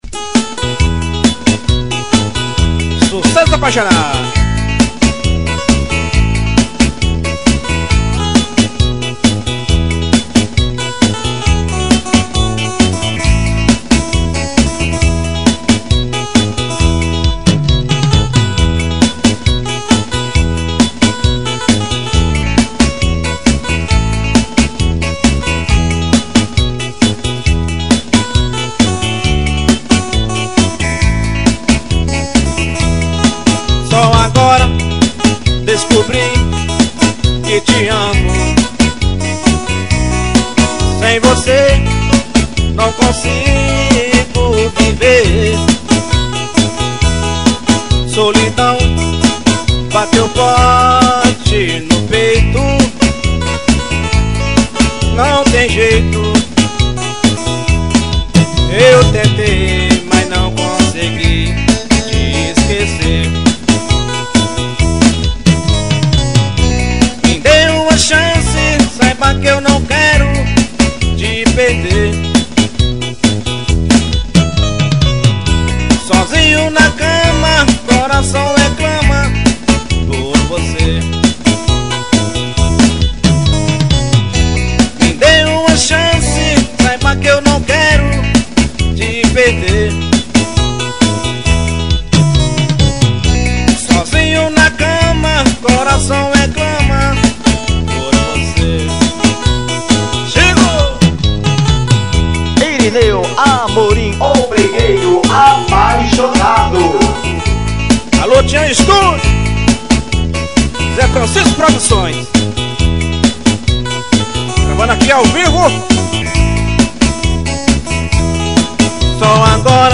Composição: brega.